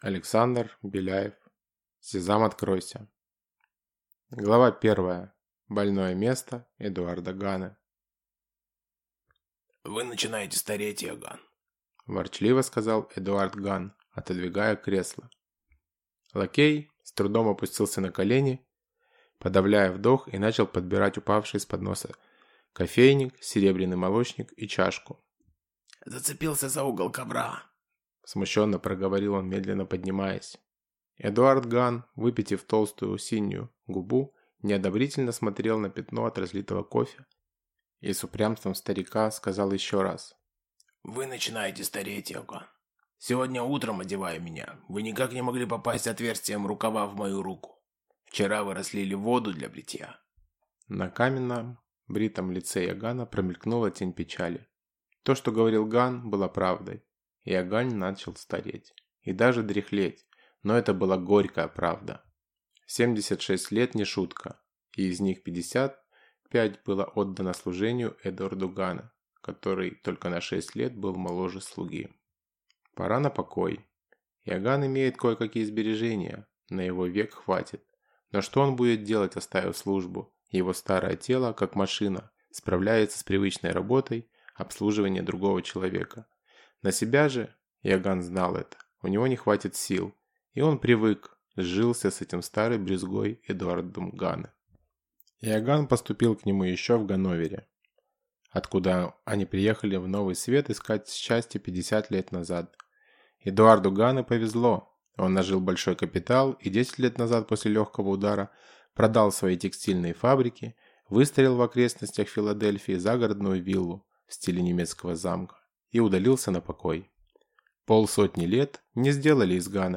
Аудиокнига Сезам, откройся!!!